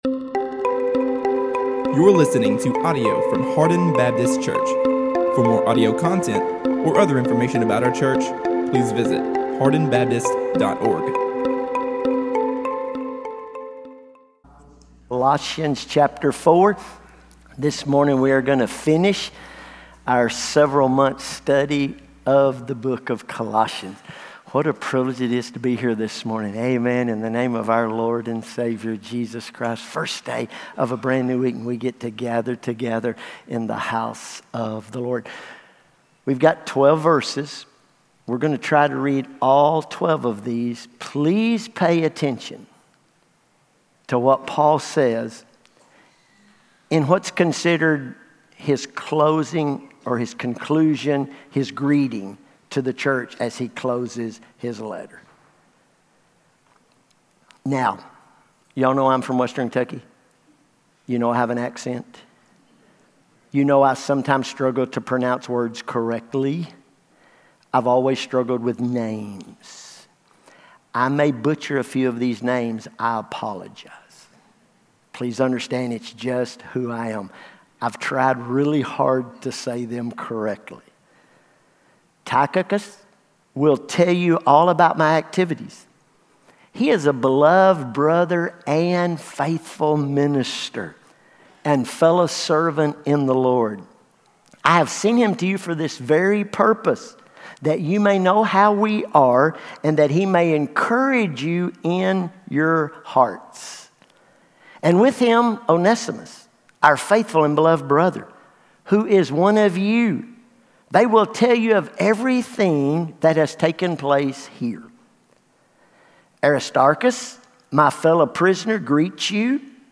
All Sermons – Hardin Baptist Church